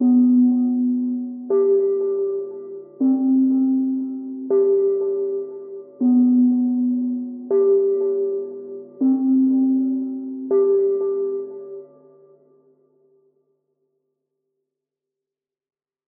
AV_Moonlight_Bell_160bpm_Emin.wav